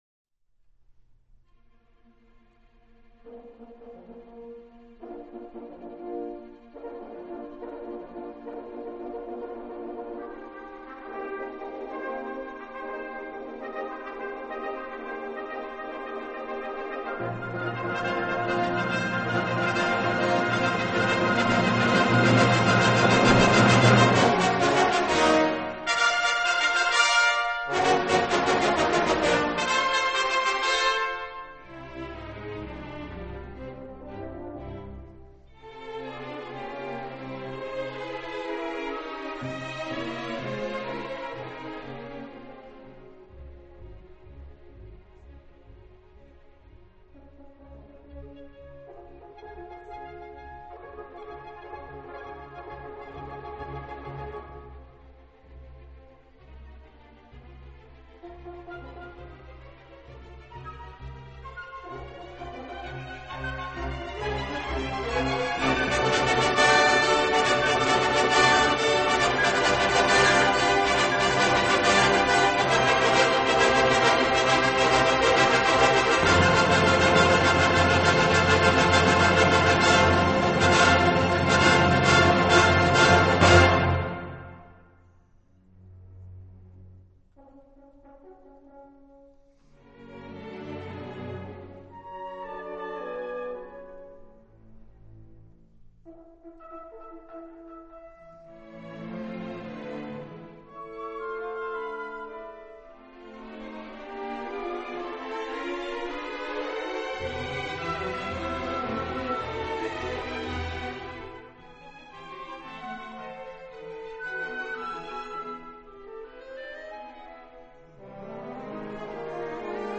音樂類型：古典音樂